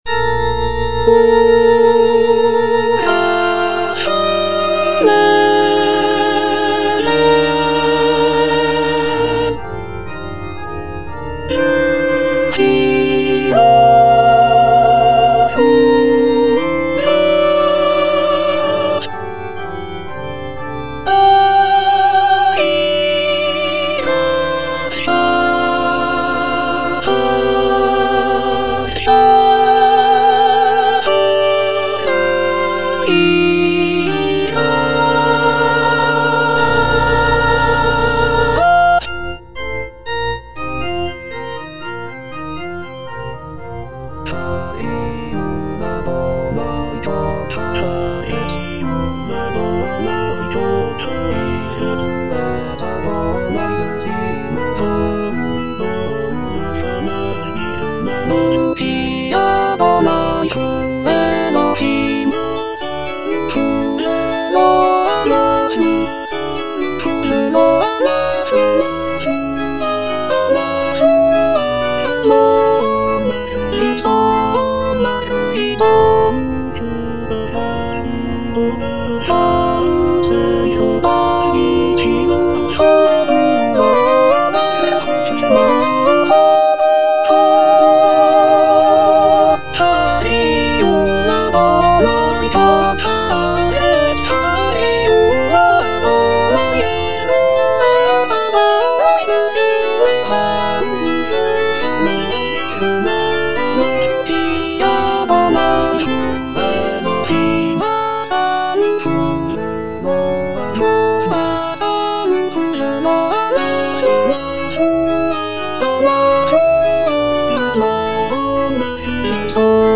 alto I) (Emphasised voice and other voices) Ads stop